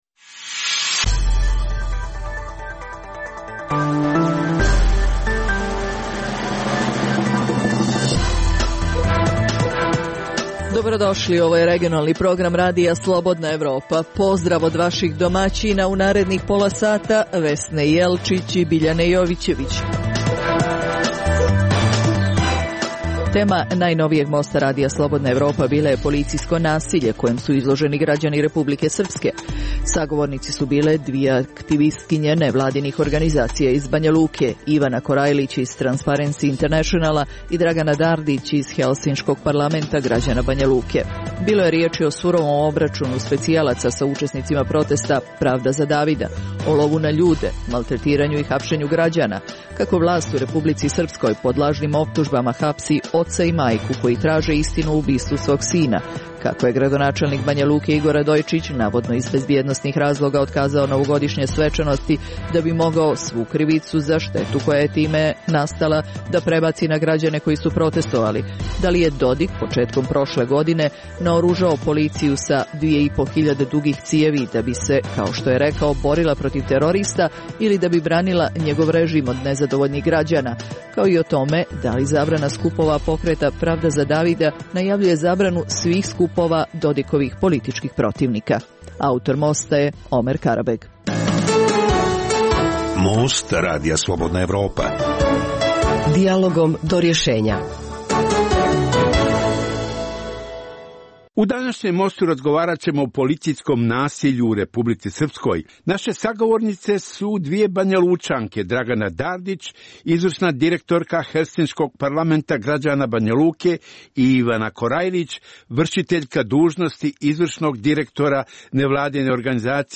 u kojem ugledni sagovornici iz regiona razmatraju aktuelne teme. Drugi dio emisije čini program "Pred licem pravde" o suđenjima za ratne zločine na prostoru bivše Jugoslavije.